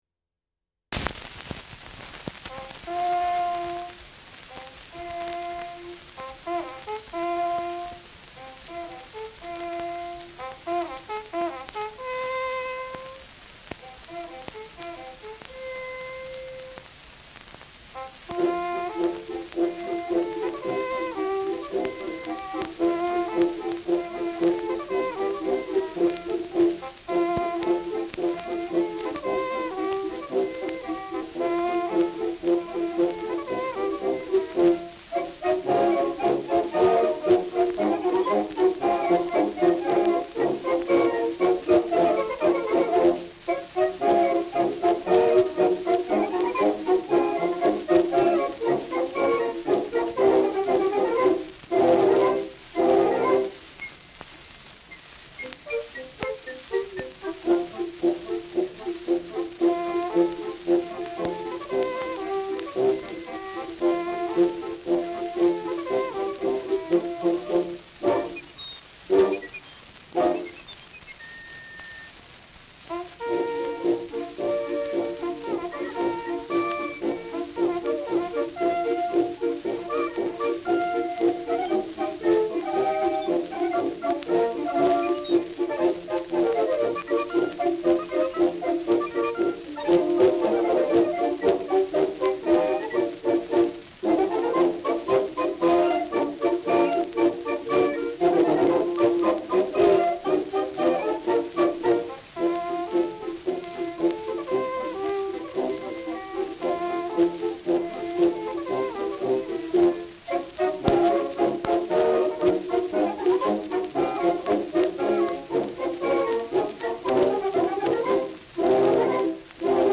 Schellackplatte
[Nürnberg?] (Aufnahmeort)